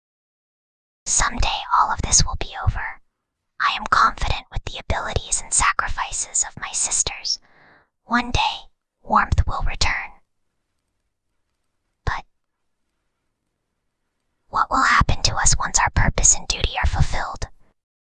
Whispering_Girl_30.mp3